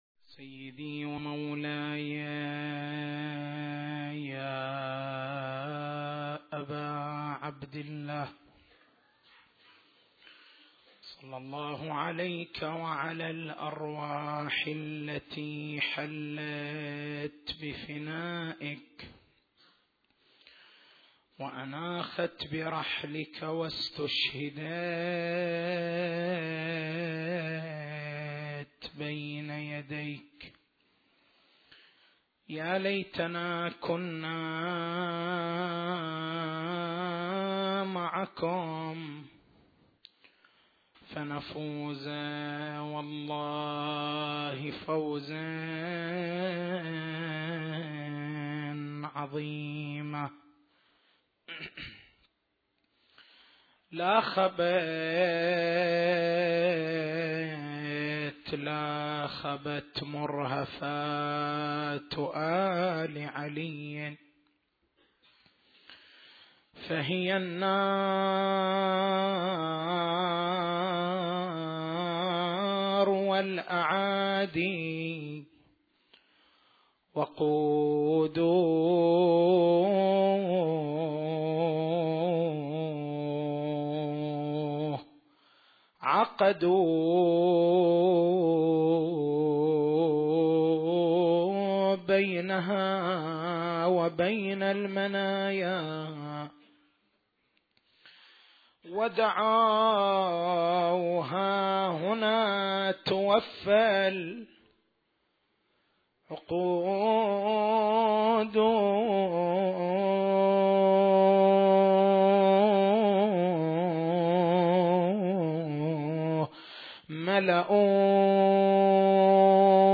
نصّ المحاضرة